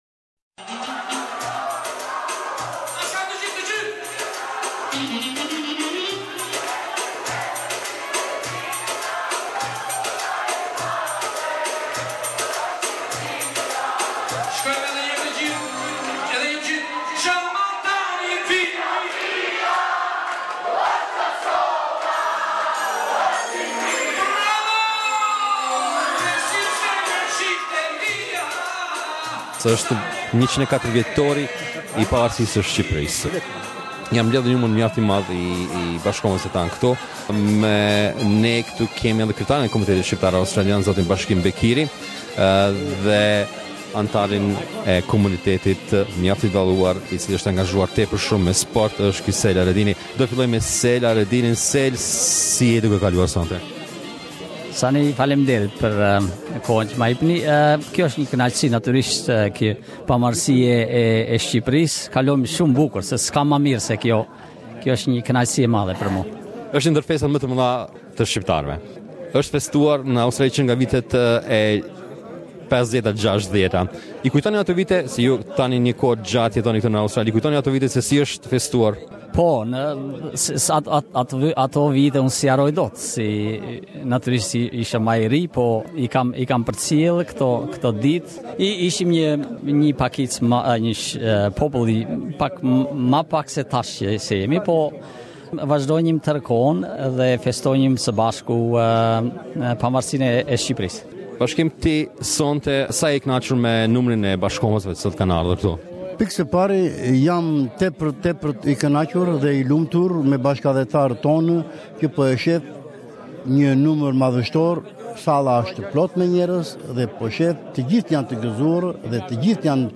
Ata u mblodhen ne disa nga lokalet e Melburnit neper darka festive te organizuar nga Shoqatat e ndryshme te Komunitetit Shqiptar - Australian. Stafi i Radios SBS ju sjell disa pjese te zgjedhura nga aktivitetet e mbajtura aty.